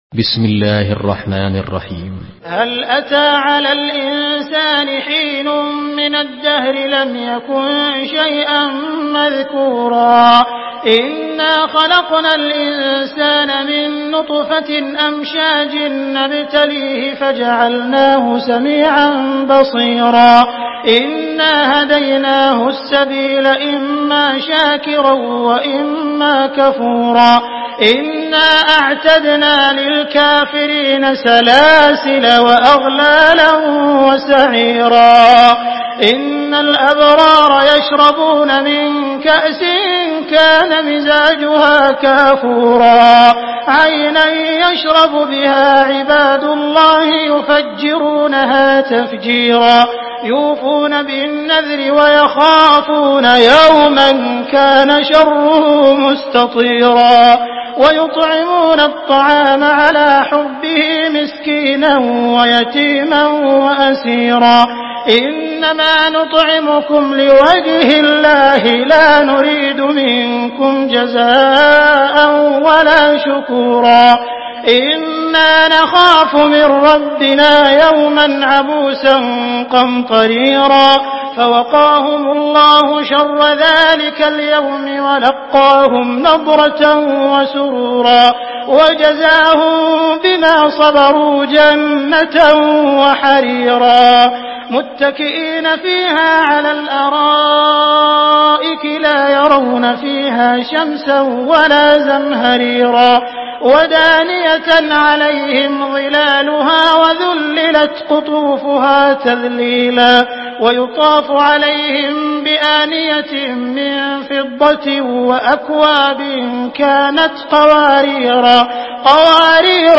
Surah Insan MP3 in the Voice of Abdul Rahman Al Sudais in Hafs Narration
Murattal Hafs An Asim